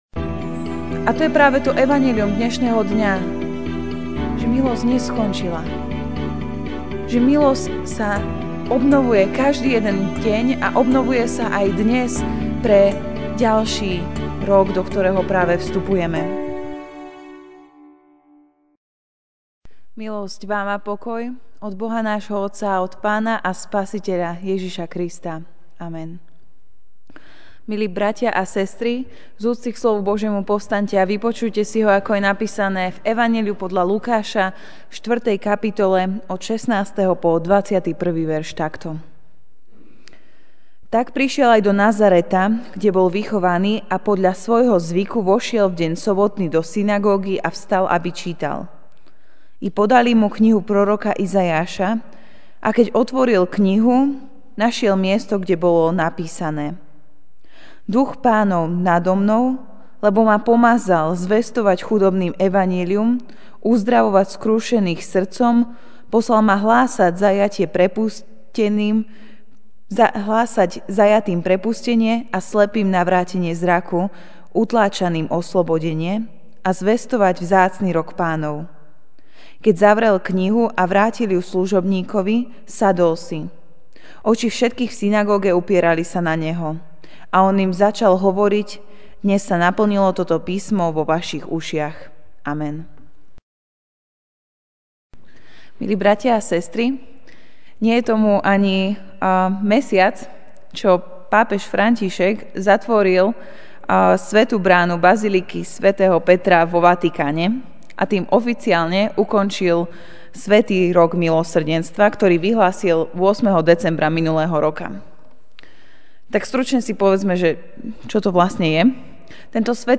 Večerná kázeň: Rok milosrdenstva (Lk. 4, 16-21) Tak prišiel aj do Nazareta, kde bol vychovaný, a podľa svojho zvyku vošiel v deň sobotný do synagógy a vstal, aby čítal.